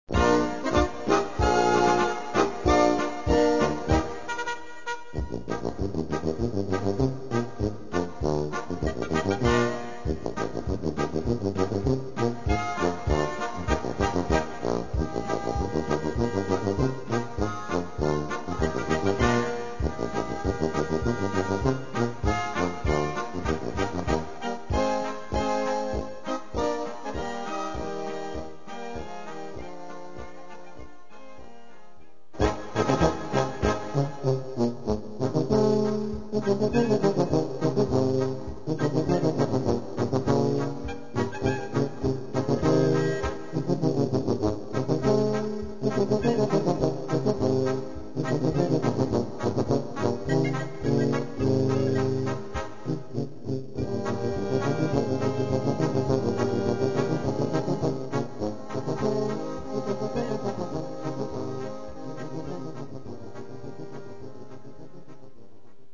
Gattung: Solo für 2 Tuben
Besetzung: Blasorchester